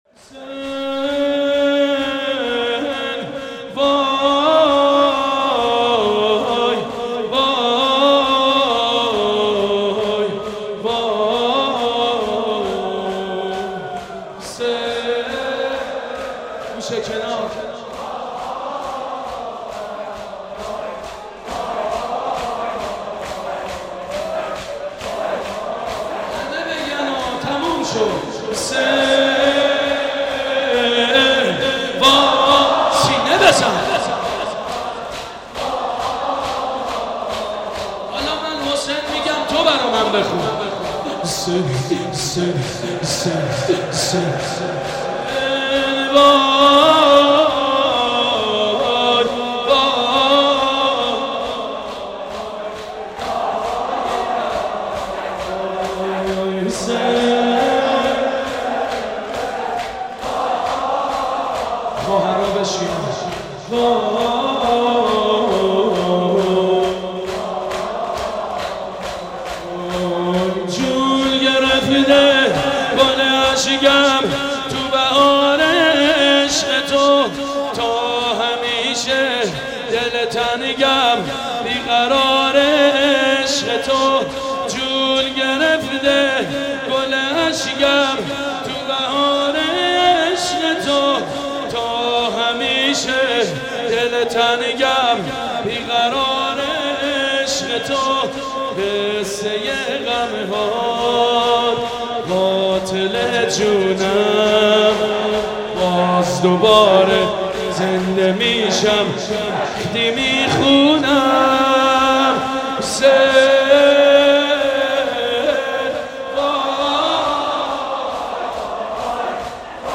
روضه سیدمجید بنی‌فاطمه